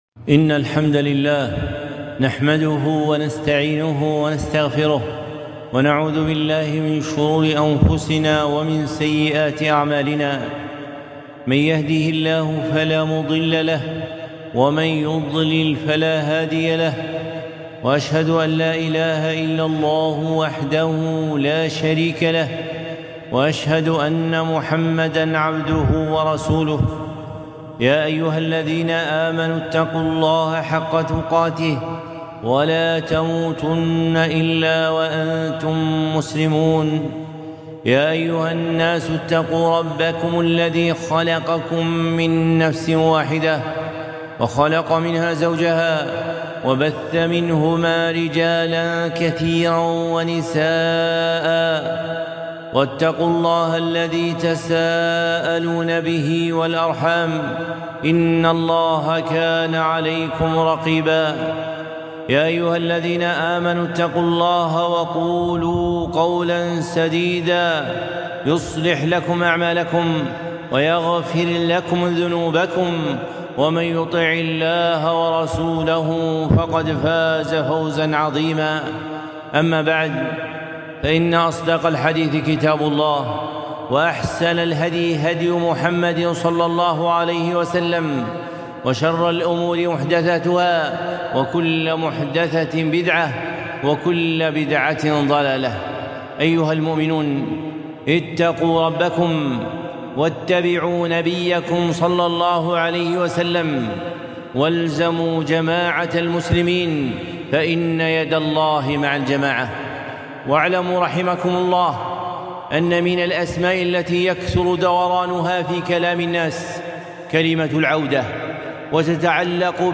خطبة - العودة إليها مرة أخرى ٢٨ المحرم ١٤٤٤